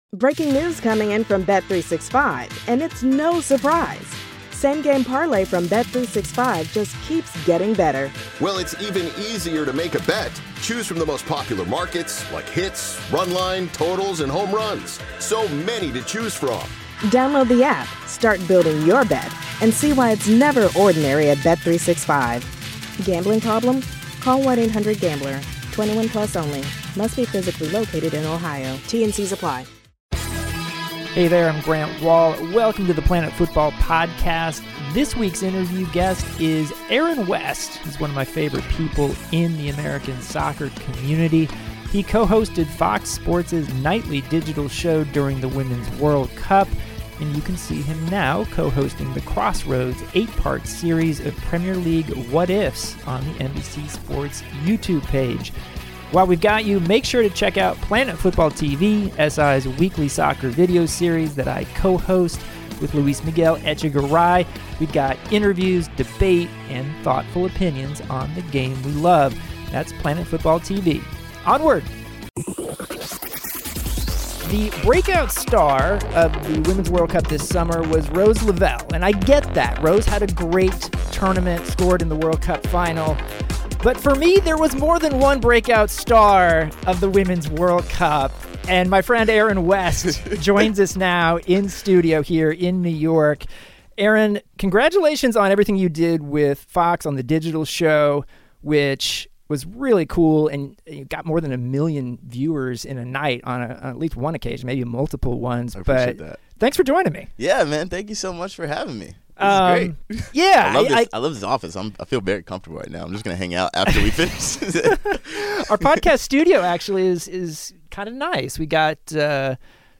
Grant has a fun conversation